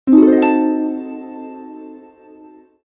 Harp Sweep Transition Sound Effect – Elegant Cinematic Whoosh
Harp sweep transition sound effect, elegant cinematic whoosh.
This elegant harp sweep transition sound effect is great for video editing, Reels, TikTok, and cinematic projects. It works well for joining two video clips or moving smoothly from scene to scene, adding a magical, polished touch.
Harp-sweep-transition-sound-effect-elegant-cinematic-whoosh.mp3